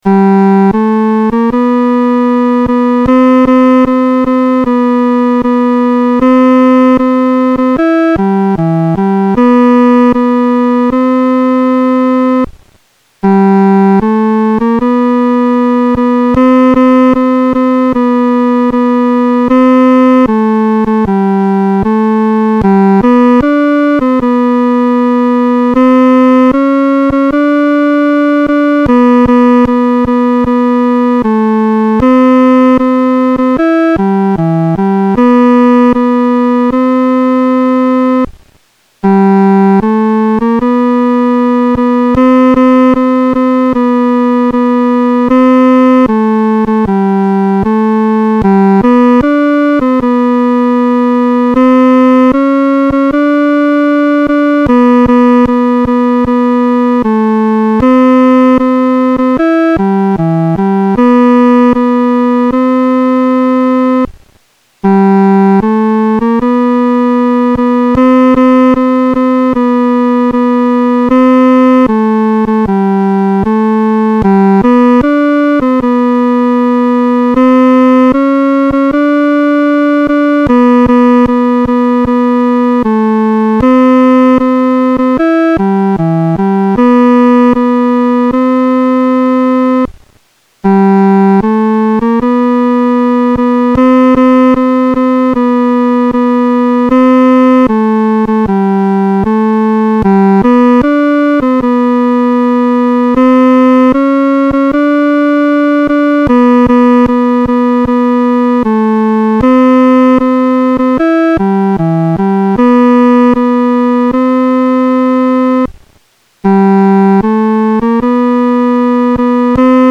独奏（第三声）